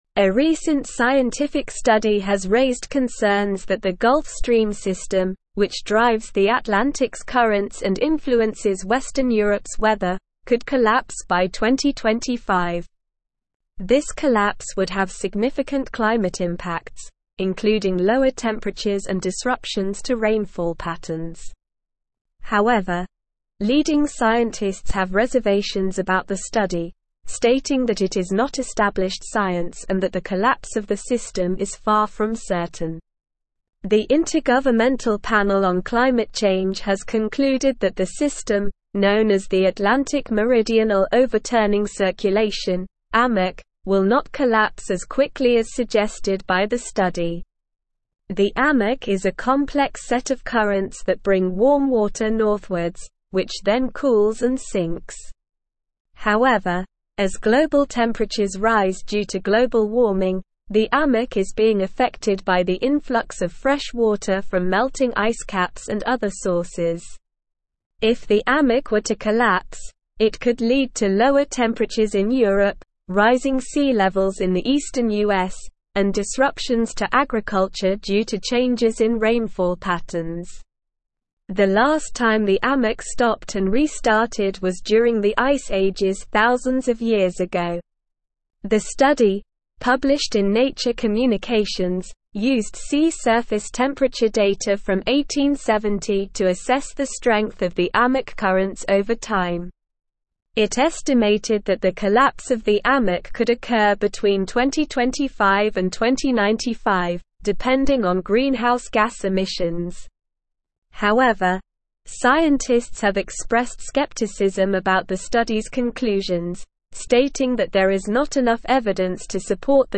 Slow
English-Newsroom-Advanced-SLOW-Reading-Gulf-Stream-System-Could-Collapse-Scientists-Express-Reservations.mp3